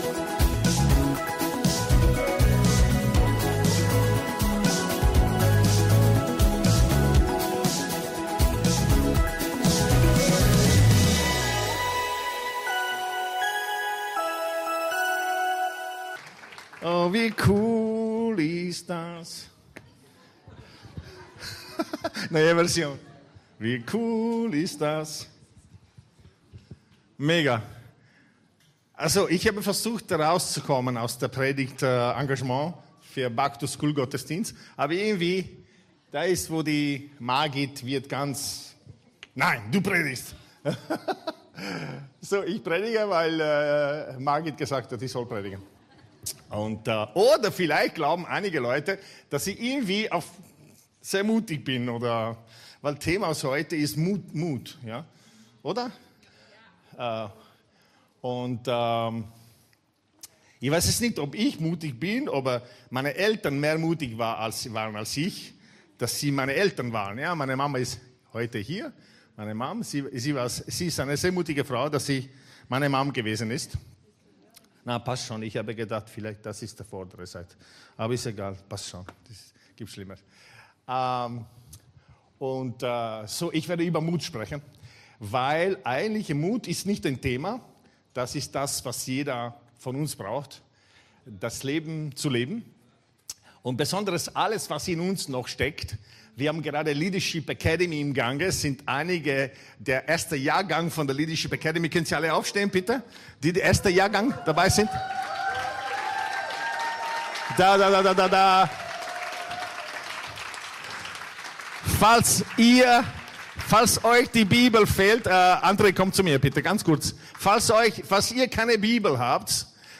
Gottesdienst live aus der LIFE Church Wien.